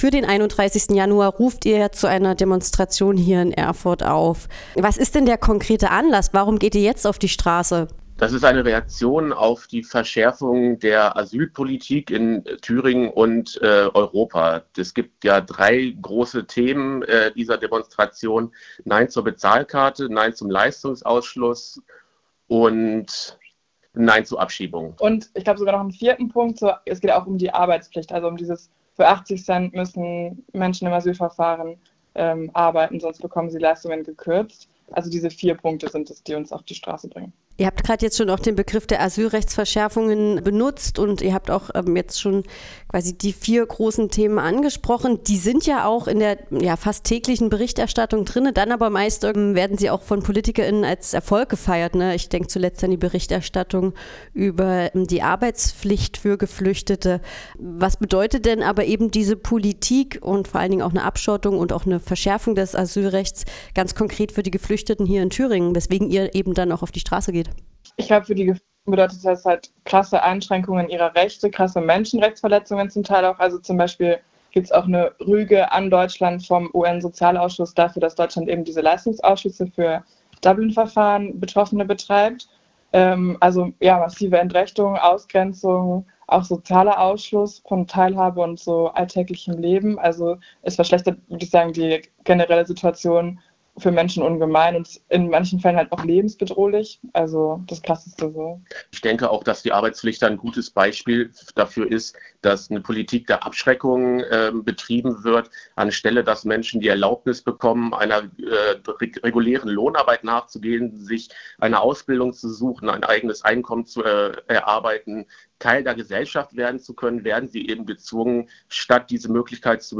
Demonstration für eine solidarische Asylpolitik | Interview zur Hintergründen und Forderungen